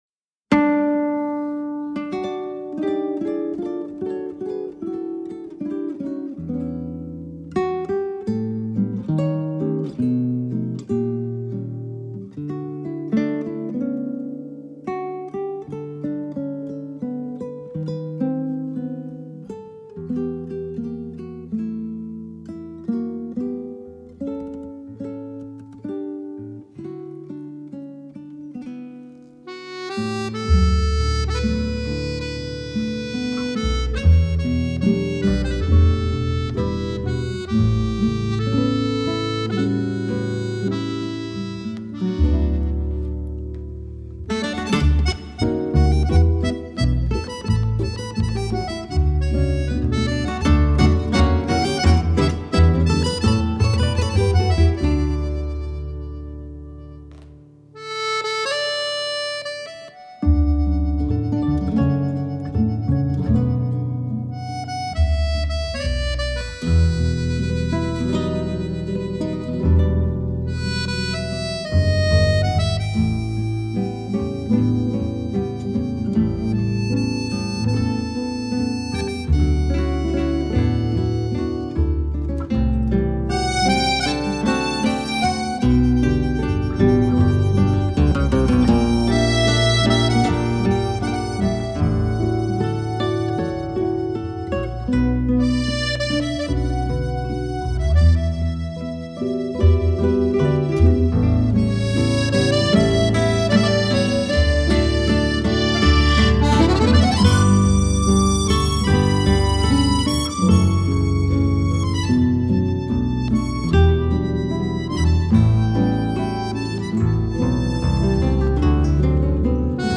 Tags: Violão e Banda
Gaita Ponto
Violão 7 Cordas
Contrabaixo